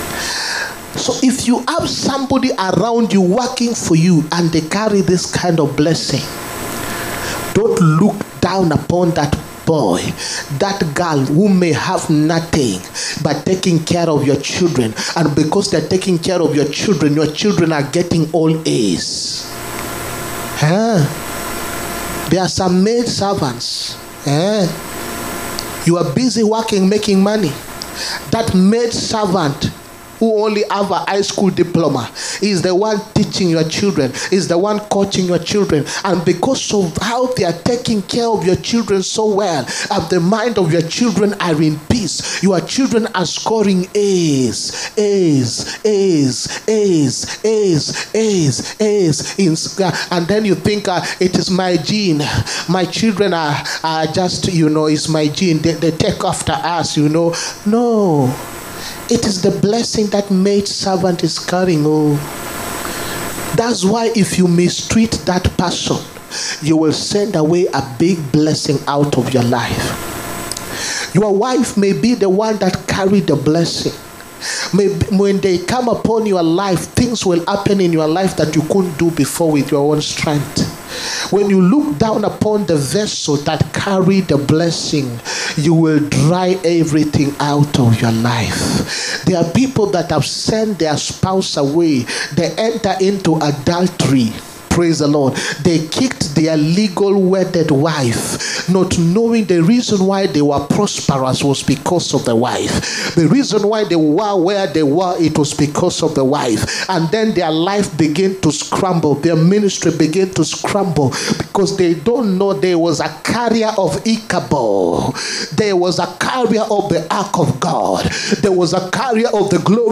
SUNDAY BLESSING SERVICE. A CARRIER OF GENERATIONAL BLESSINGS. 1ST DECEMBER 2024.